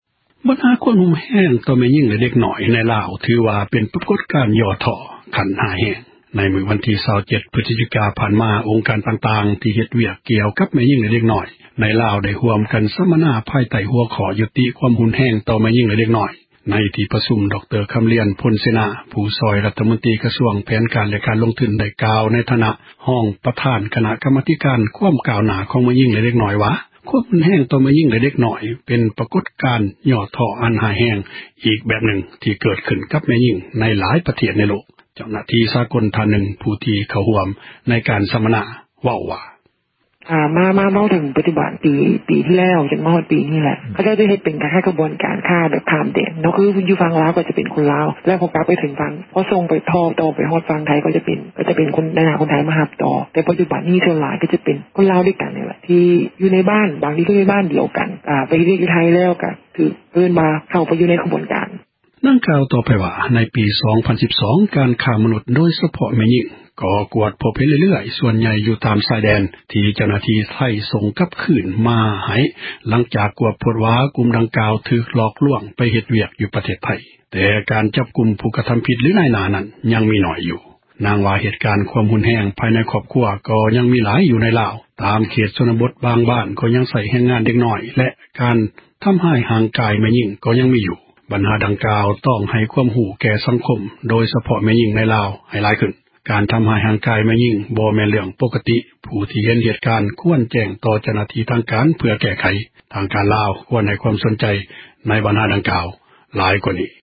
ເຈົ້າຫນ້າທີ່ ສາກົນ ທ່ານນື່ງ ຜູ້ທີ່ໄດ້ເຂົ້າຮ່ວມ ການສຳມະນາ ເວົ້າວ່າ: